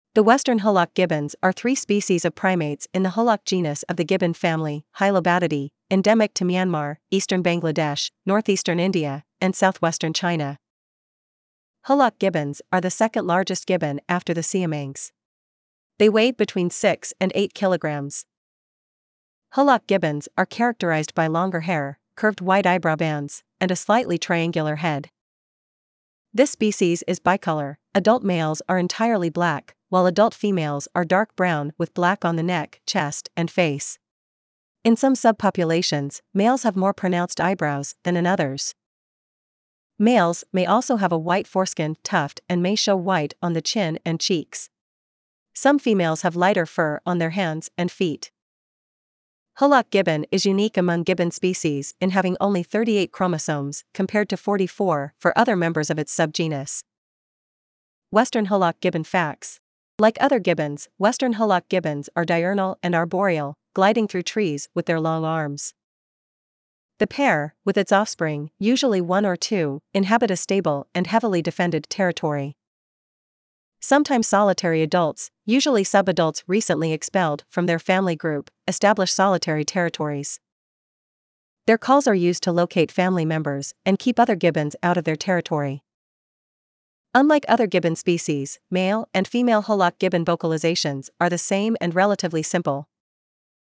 Western Hoolock Gibbon
• Unlike other gibbon species, male and female hoolock gibbon vocalizations are the same and relatively simple.
Western-Hoolock-gibbons.mp3